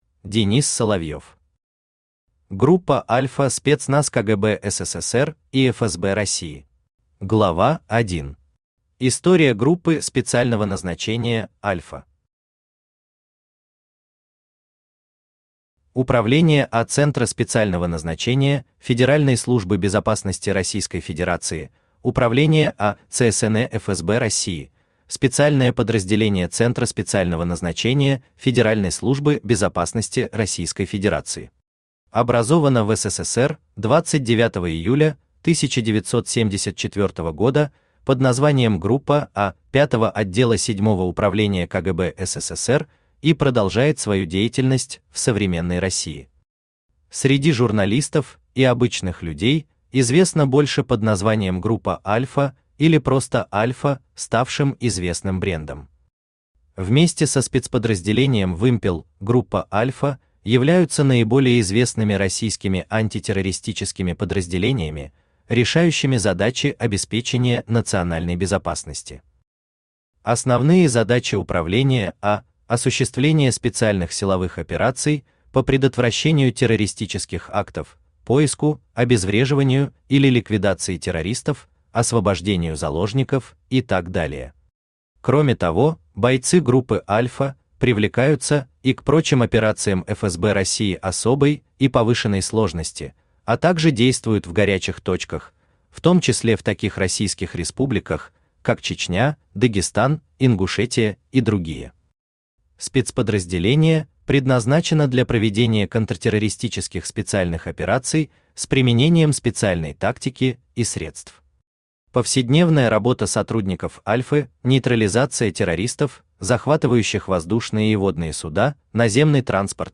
Aудиокнига Группа Альфа спецназ КГБ СССР и ФСБ России Автор Денис Соловьев Читает аудиокнигу Авточтец ЛитРес.